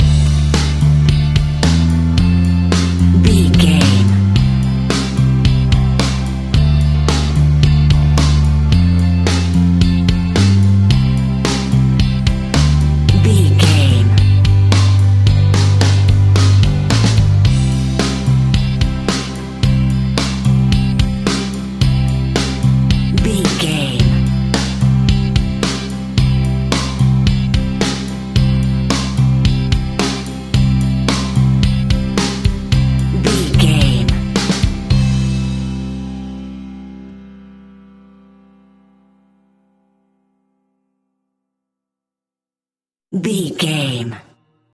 Ionian/Major
calm
melancholic
optimistic
smooth
uplifting
electric guitar
bass guitar
drums
pop rock
indie pop
instrumentals
organ